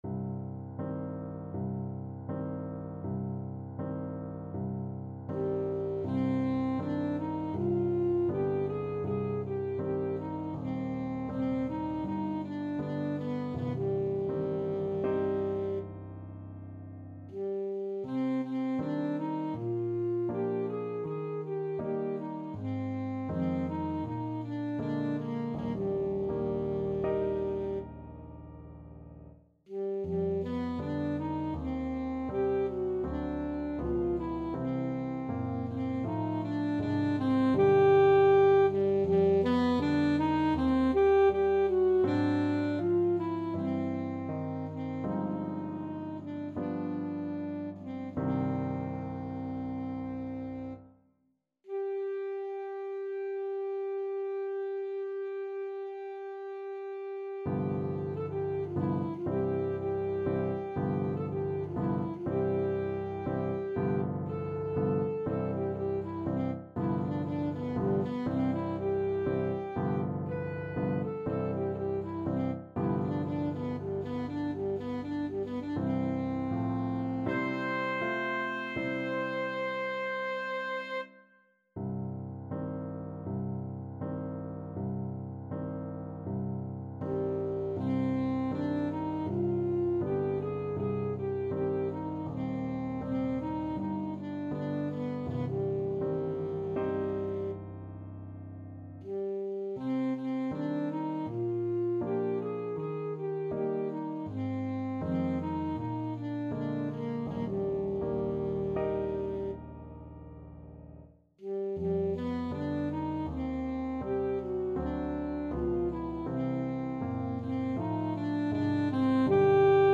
Alto Saxophone
Un poco andante
4/4 (View more 4/4 Music)
Classical (View more Classical Saxophone Music)